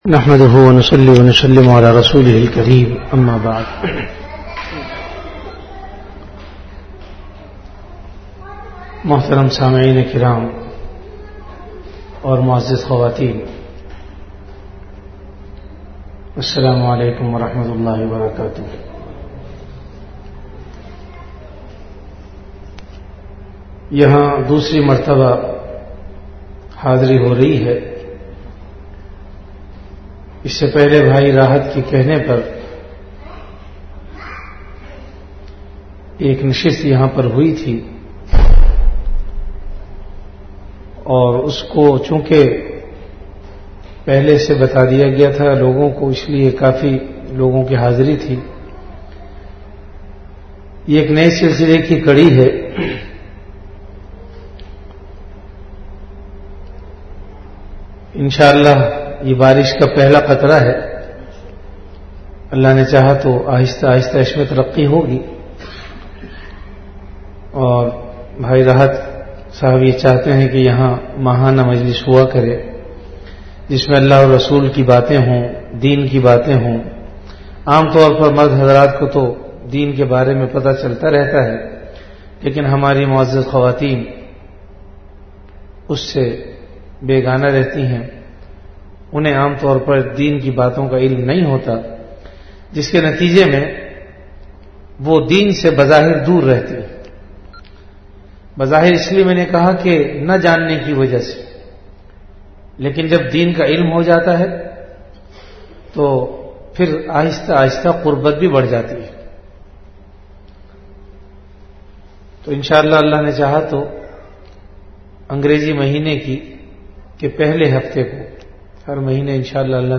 Category Bayanat
Event / Time After Isha Prayer